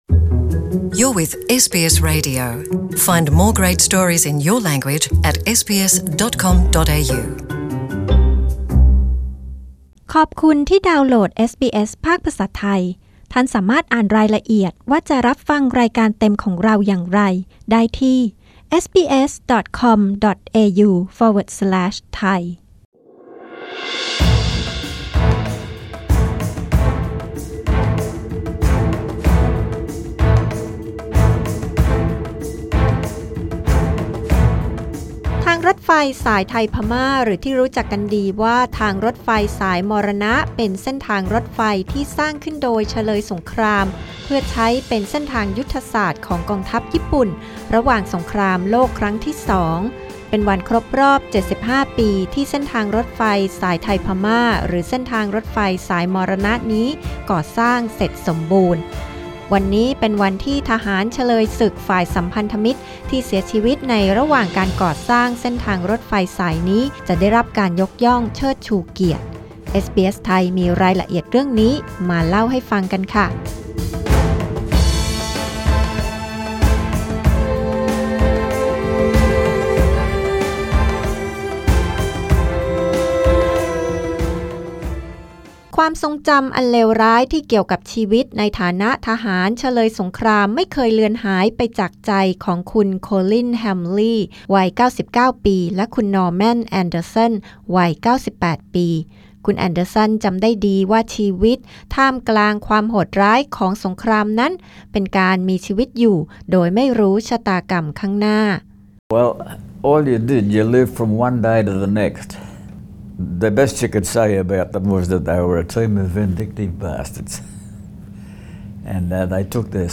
เพื่อฟังเสียงรายงานข่าวเรื่องนี้เป็นภาษาไทย